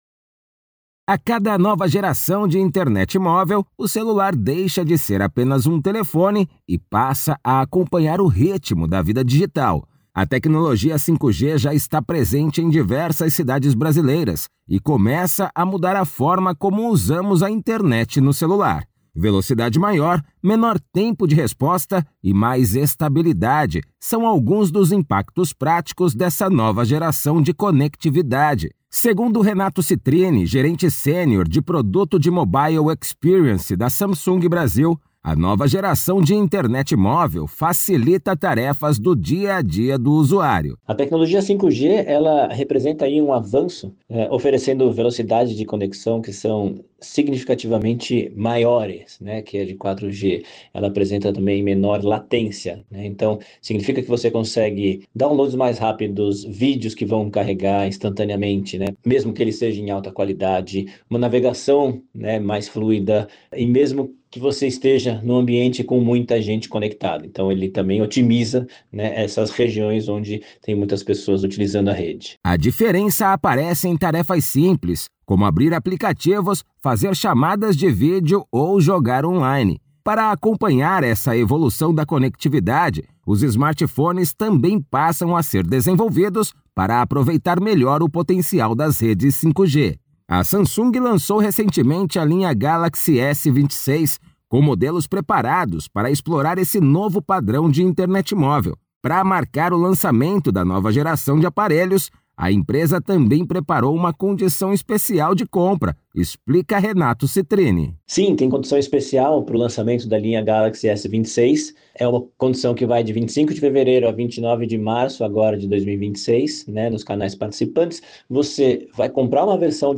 Materiais de Imprensa > Radio Release